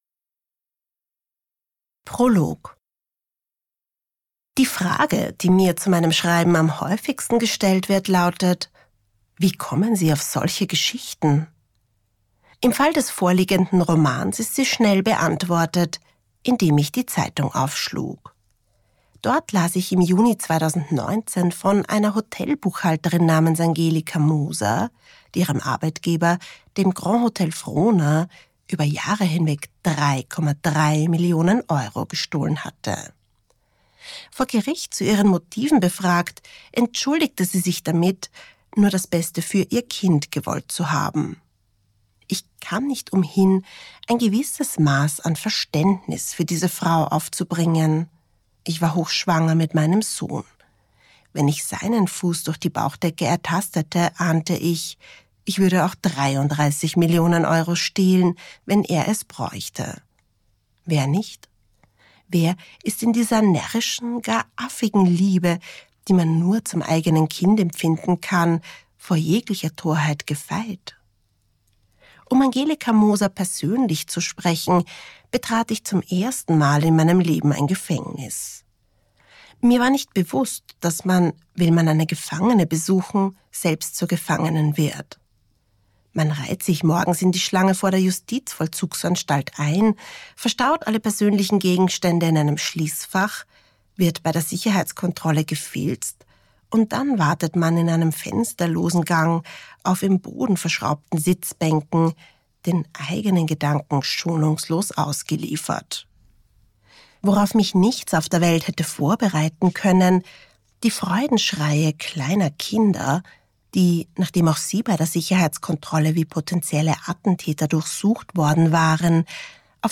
Cover Print Cover Web Hörprobe MP3